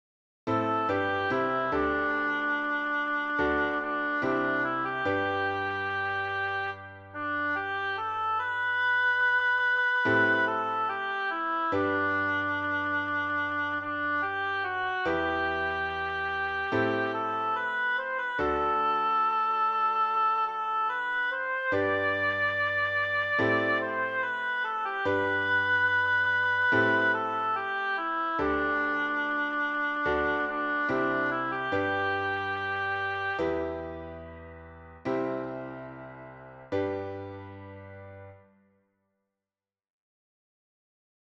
Traditional English melody